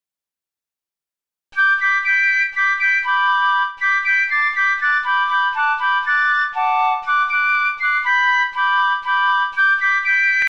Basler Märsch
(numme d Aafäng)